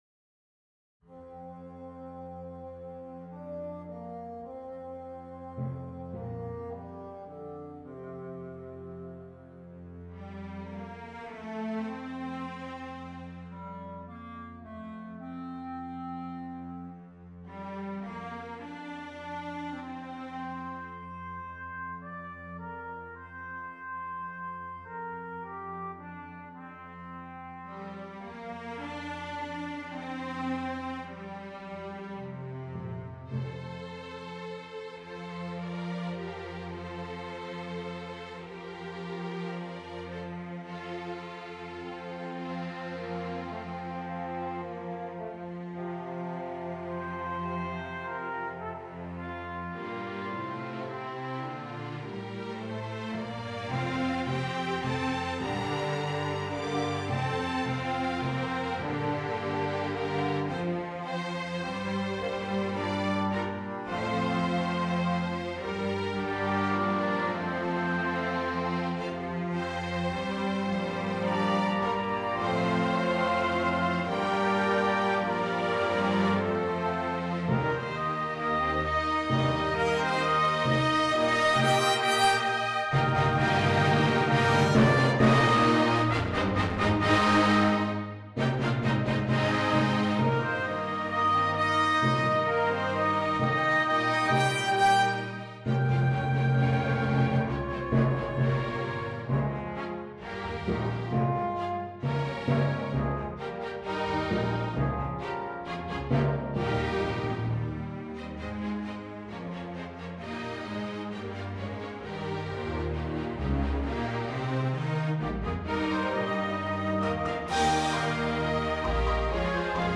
Canyon Vista Suite -for Orchestra